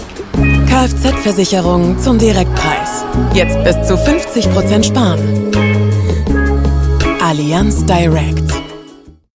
markant, sehr variabel
Mittel minus (25-45)
Norddeutsch
Commercial (Werbung), Off